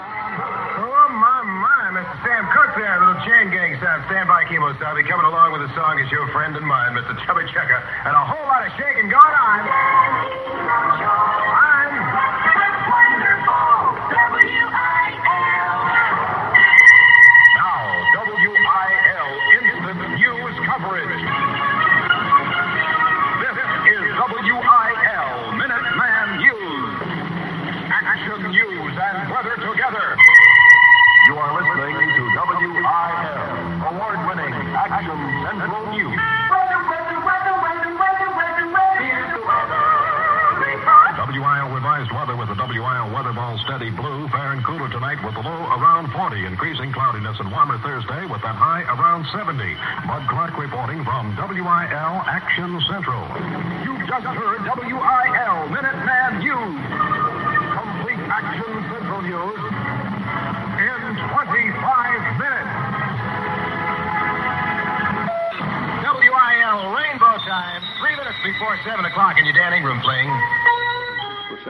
WIL Ingram, Dan aircheck · St. Louis Media History Archive